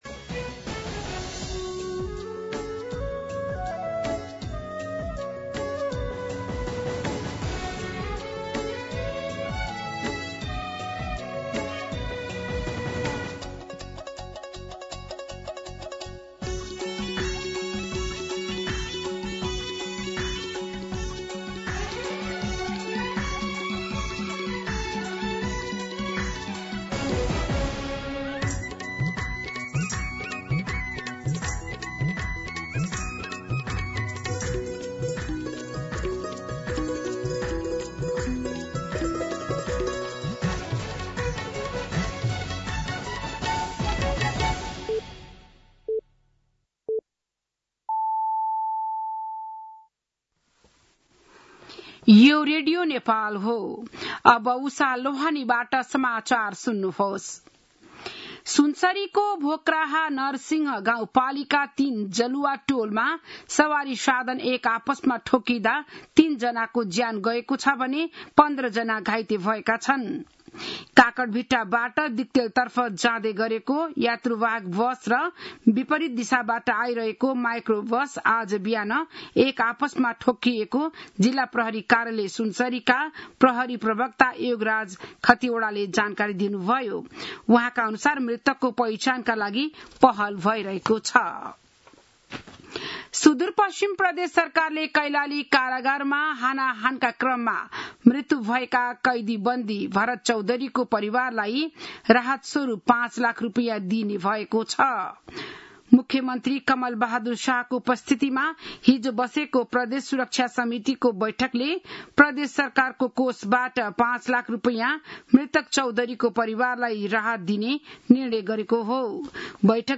बिहान ११ बजेको नेपाली समाचार : २६ साउन , २०८२
11-am-Nepali-News-4.mp3